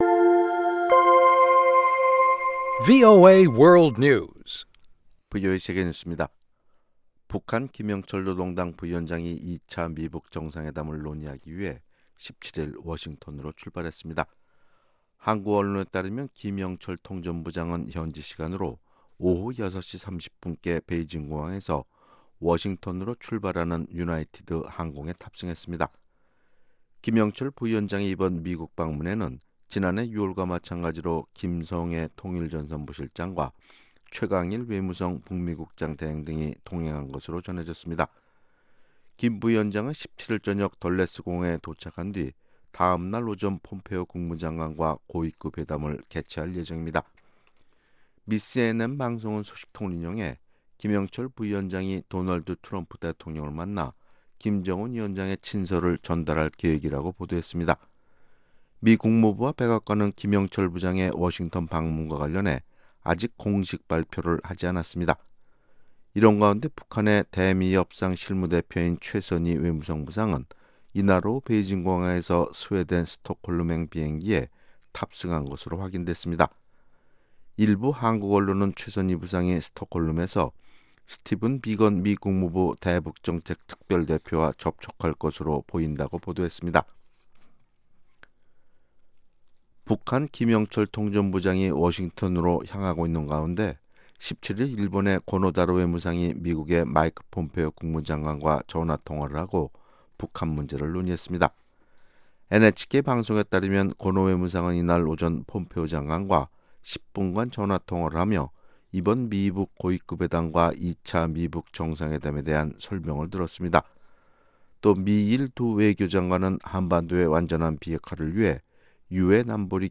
VOA 한국어 아침 뉴스 프로그램 '워싱턴 뉴스 광장' 2019년 1월 18일 방송입니다. 미 공화당 하원의원이 2차 미-북 정상회담 개최지로 베트남 하노이가 유력하다고 밝혔습니다. 국제 기독교 선교단체가 북한을 18년 연속 세계 최악의 기독교 박해국가로 지목했습니다.